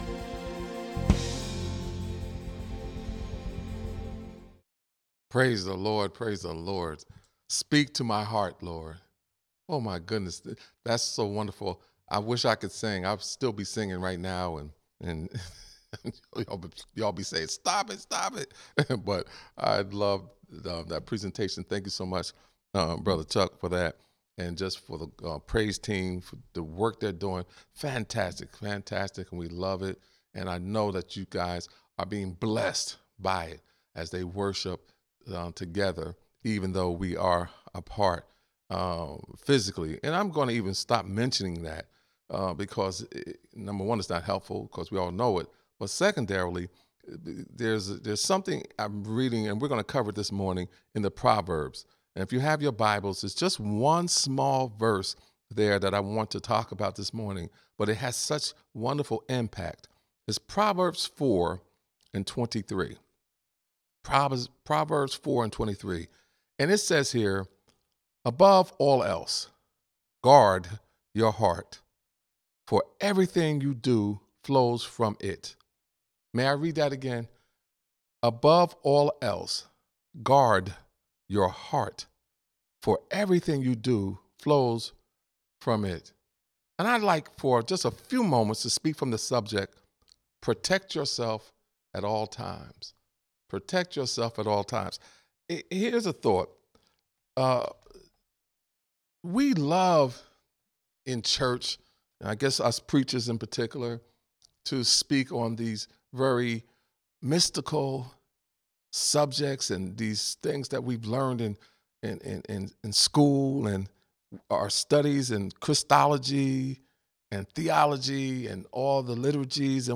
Sermons | Antioch Baptist Church